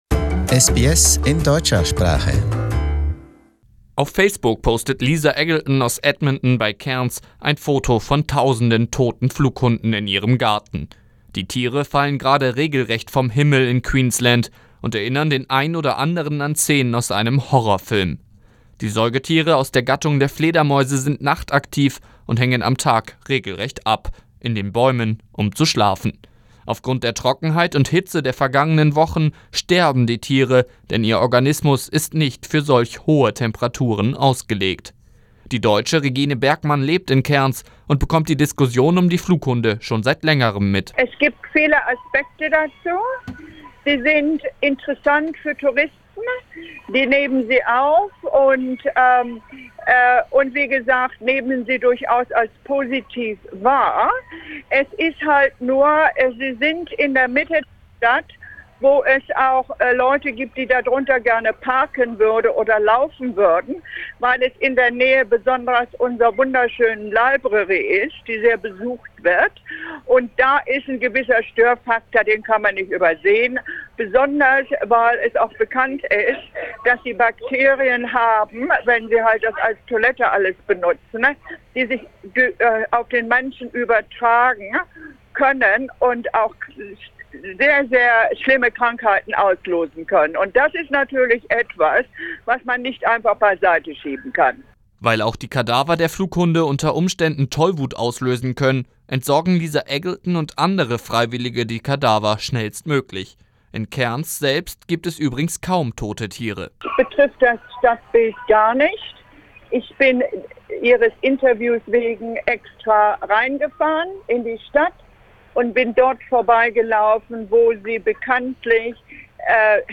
Wegen der anhaltenden Hitzewelle verenden Tausende Flughunde rund um Cairns. Wir haben mit einer Deutschen aus Cairns gesprochen.